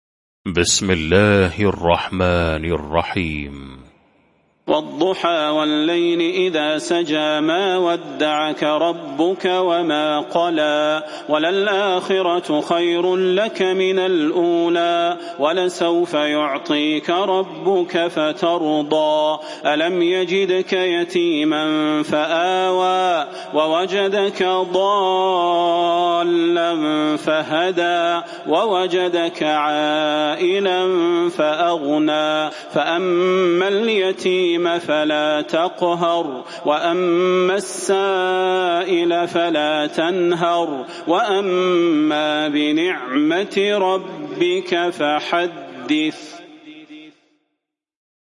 المكان: المسجد النبوي الشيخ: فضيلة الشيخ د. صلاح بن محمد البدير فضيلة الشيخ د. صلاح بن محمد البدير الضحى